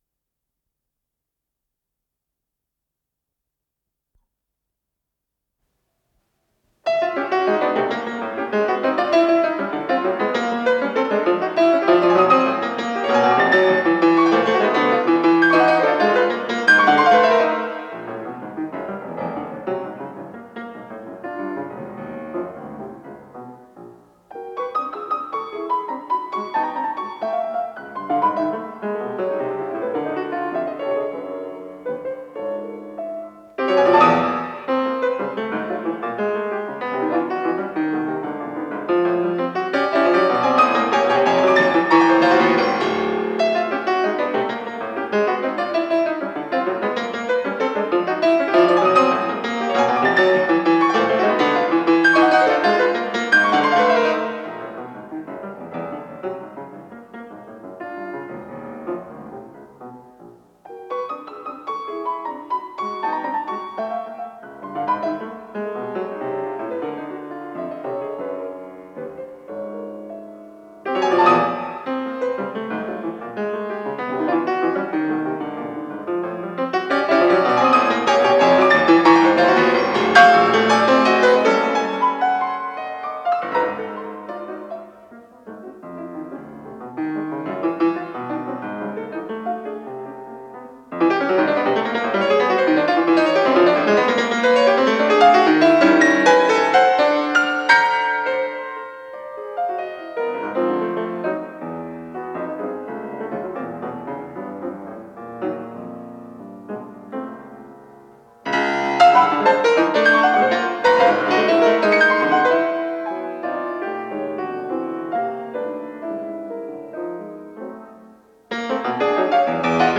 ПодзаголовокДля фортепиано
Скорость ленты38 см/с
ВариантДубль моно